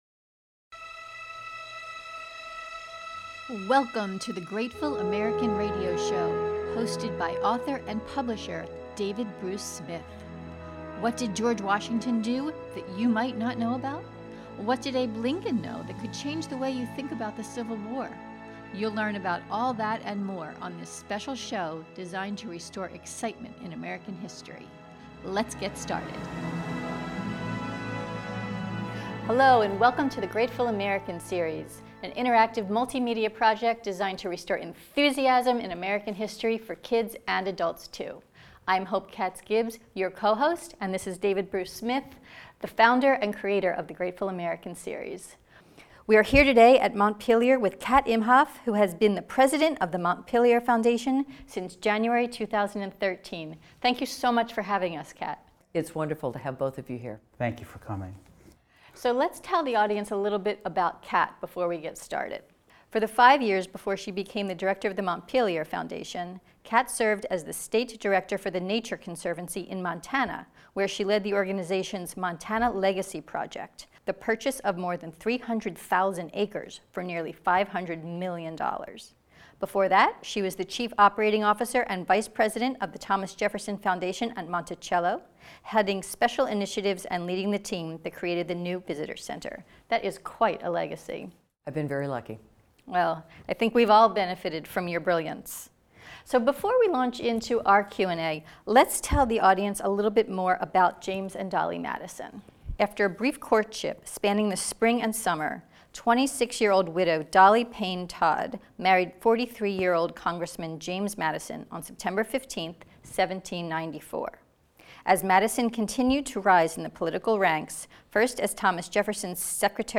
That’s just the start of what you’ll learn in this podcast interview, including: Details about Madison’s contributions to the Constitution.